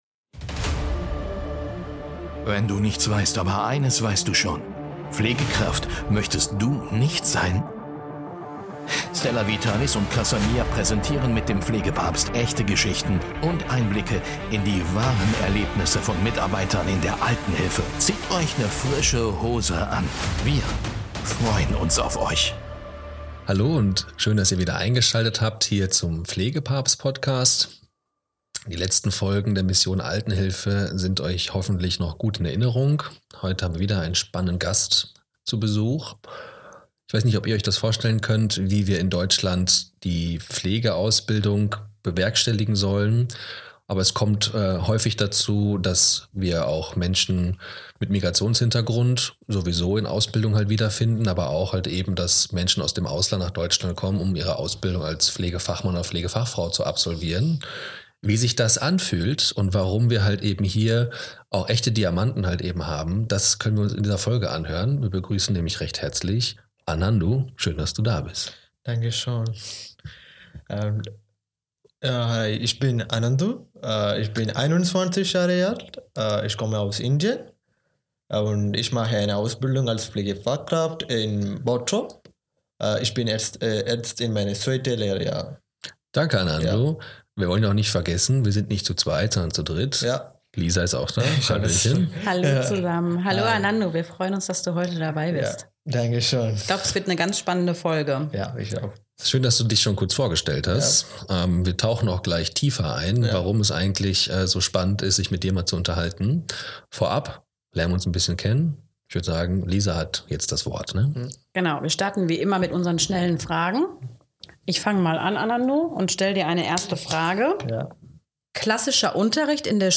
Wie können wir diese Potenziale besser begleiten – statt sie zu bremsen? Diese Folge ist ein Plädoyer für mehr Empathie, mehr Diversität – und mehr Respekt für unsere Azubis. Ein Gespräch, das berührt, inspiriert und zum Nachdenken anregt.